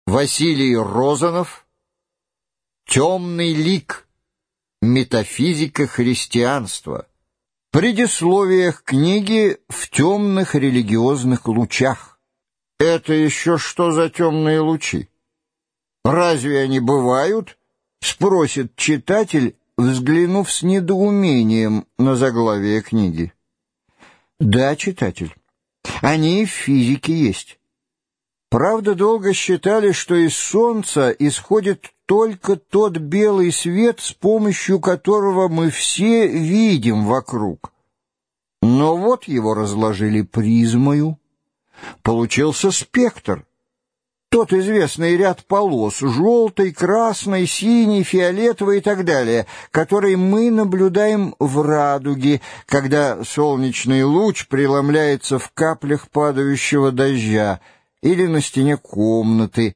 Аудиокнига Темный лик. Метафизика христианства | Библиотека аудиокниг